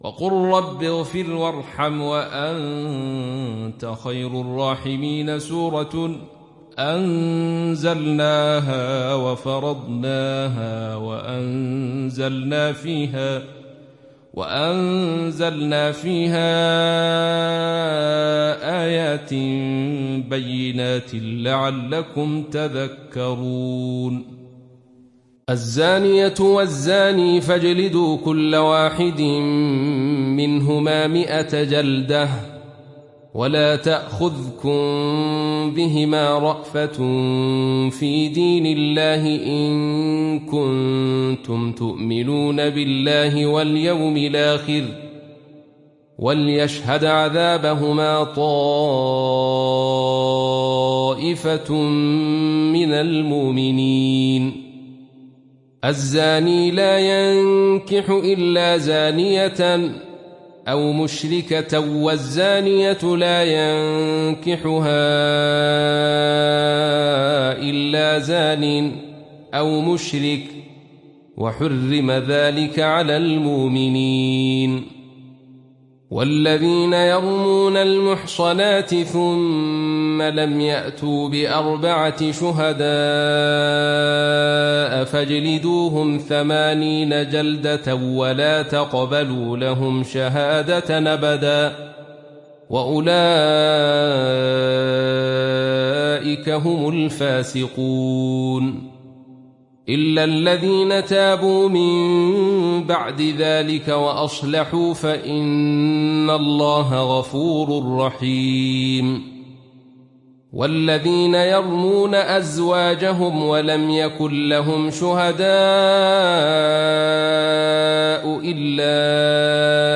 تحميل سورة النور mp3 بصوت عبد الرشيد صوفي برواية خلف عن حمزة, تحميل استماع القرآن الكريم على الجوال mp3 كاملا بروابط مباشرة وسريعة